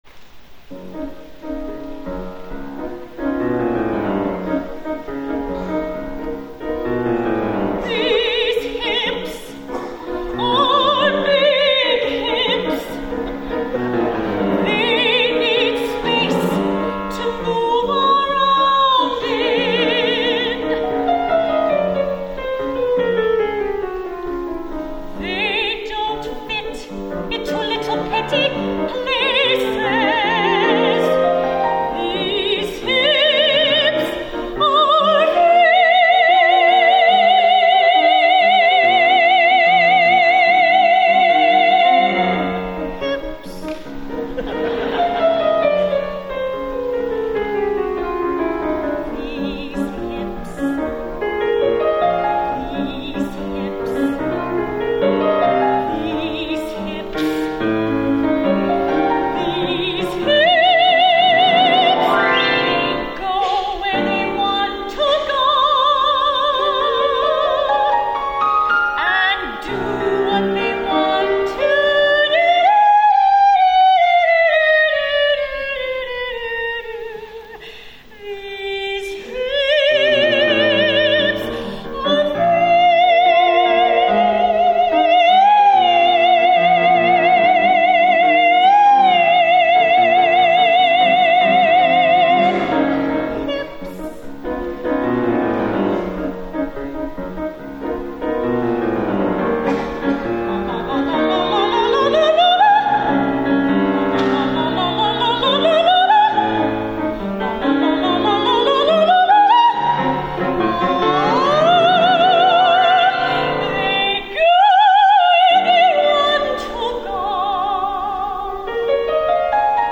for Soprano and Piano (1997)